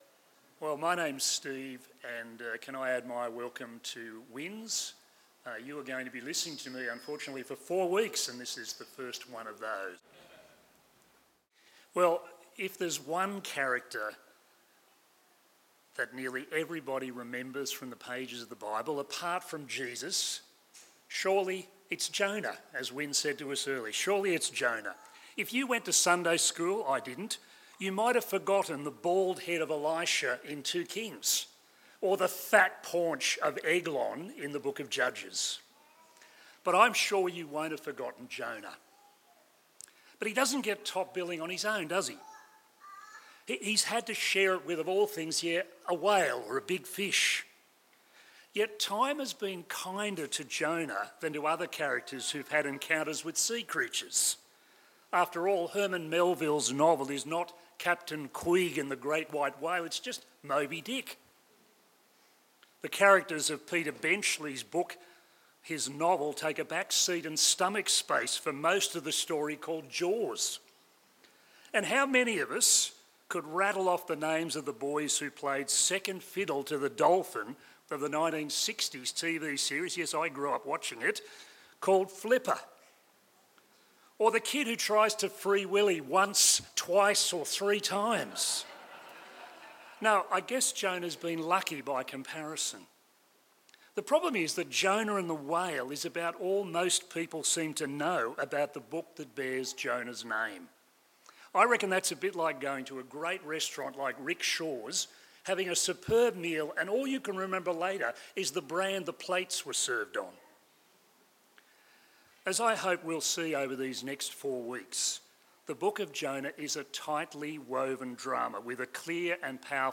Sermons
Series of Bible talks from Leviticus